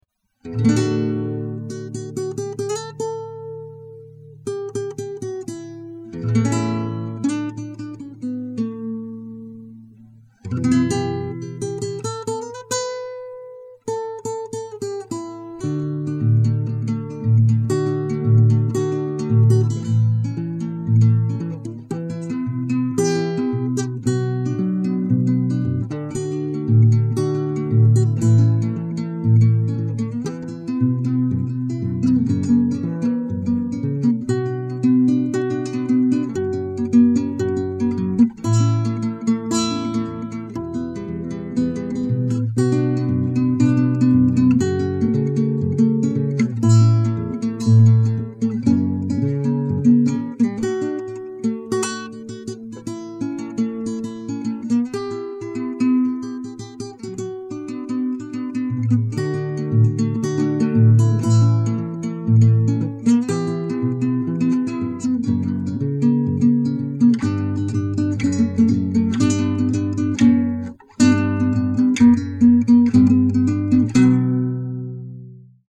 си-минор